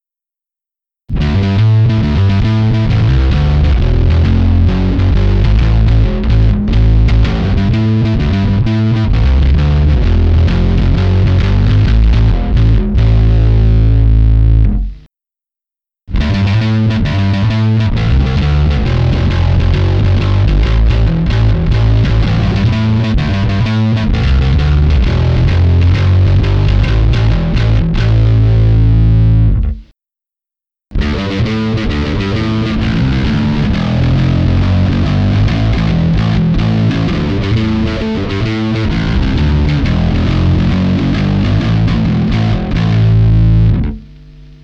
Všechny následující ukázky jsou s plně otevřenou tónovou clonou.
A vůbec špatně nezní ani fuzz, ten jsem pro změnu nahrál trsátkem.